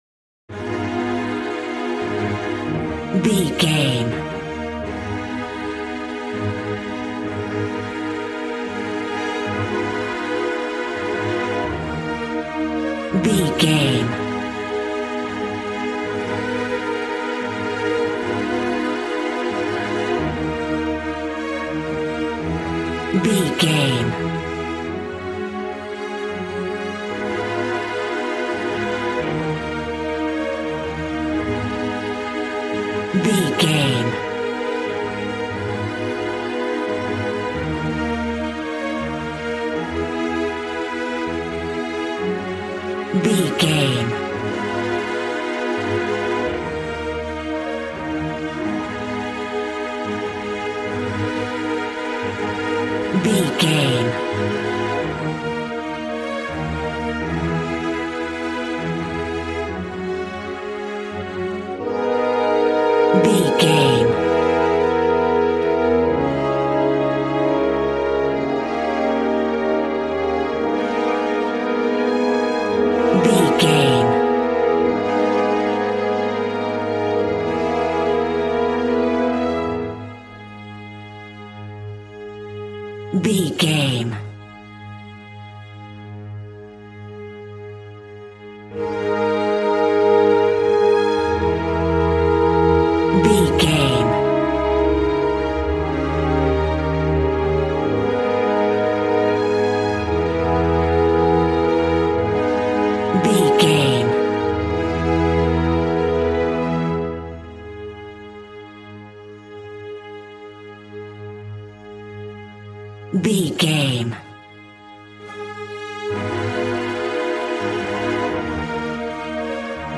A classical music mood from the orchestra.
Regal and romantic, a classy piece of classical music.
Aeolian/Minor
A♭
regal
cello
violin
strings